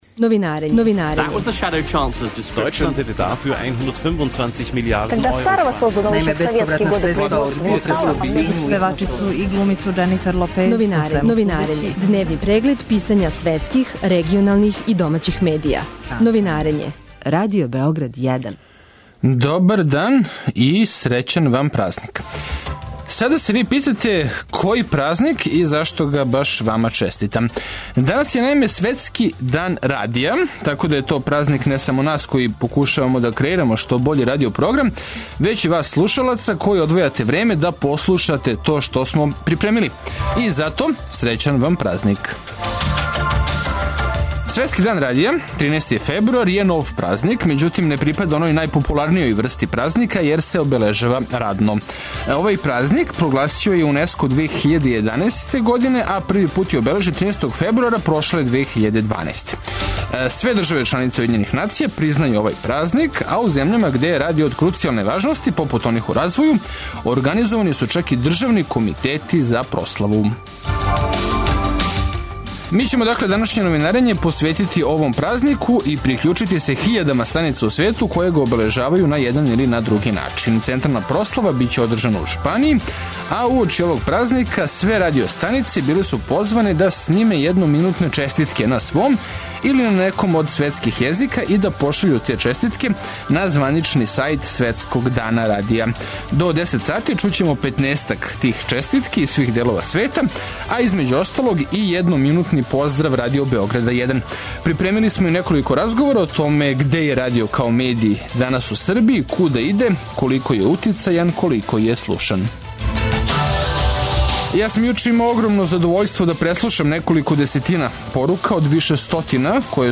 Поводом Светског дана радија, станице на целој планети позване су да пошаљу једноминутну поруку, и овом приликом чућемо неке од њих, а разговараћемо и са медијским стручњацима из наше земље о томе каква је позиција радија данас у Србији и шта би могла да крије будућност.